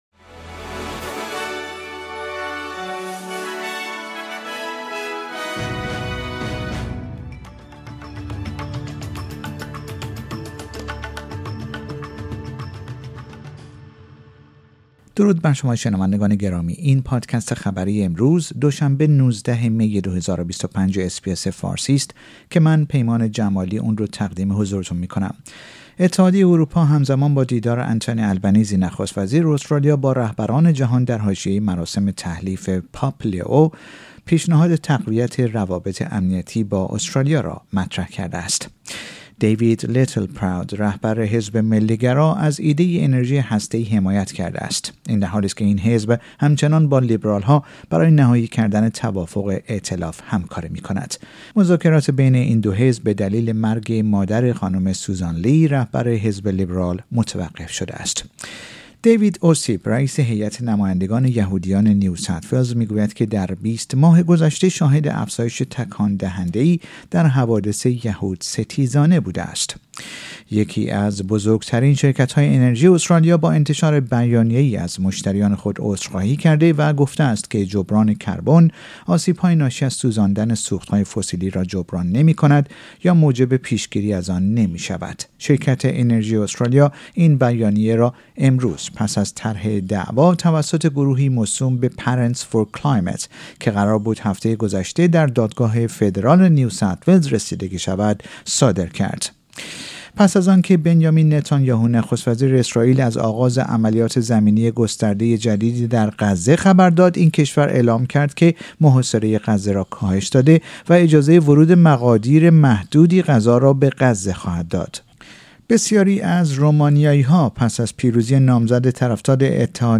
در این پادکست خبری مهمترین اخبار امروز دوشنبه ۱۹ می ارائه شده است.